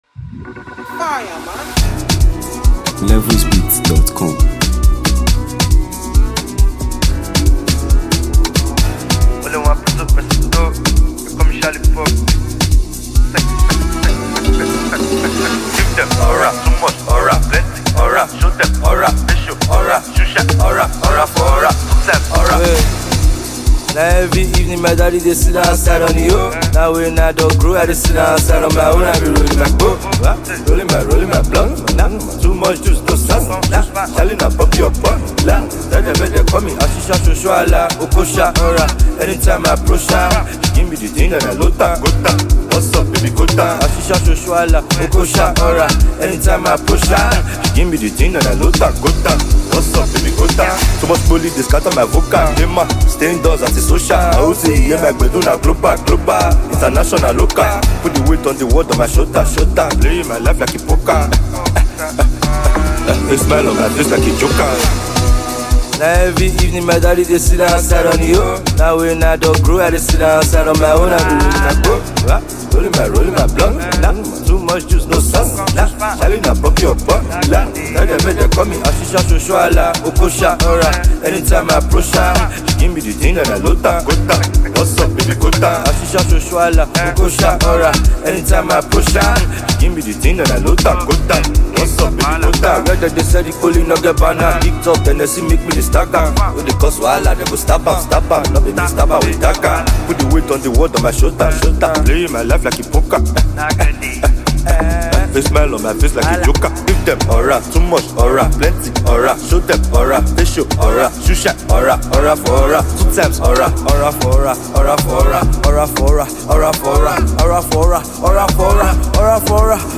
melodic brilliance
delivering infectious beats, memorable hooks